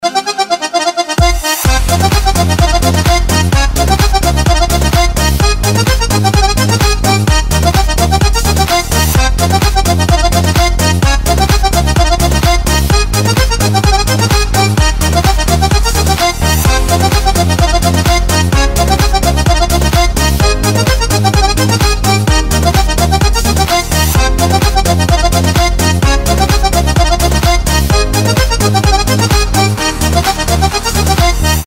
• Качество: 320, Stereo
громкие
веселые
dance
быстрые
house
Южно-Американская заводная музыка